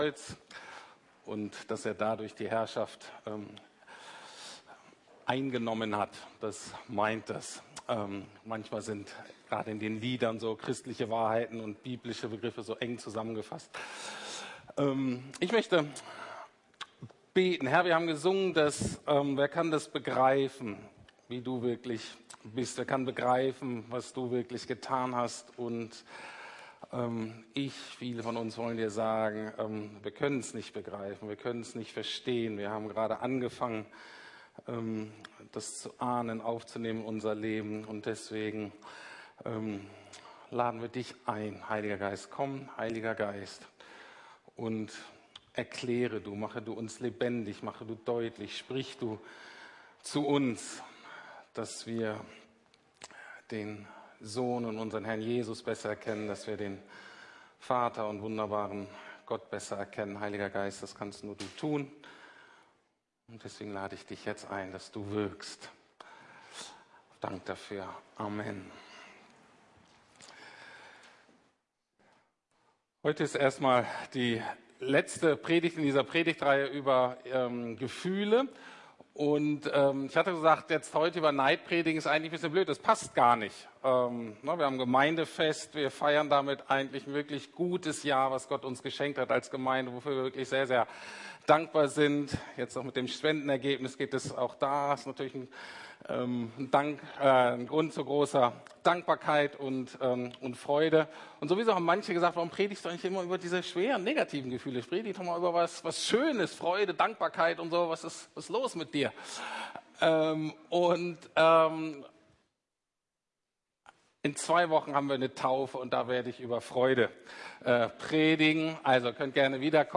Jesus ist größer als mein Neid! ~ Predigten der LUKAS GEMEINDE Podcast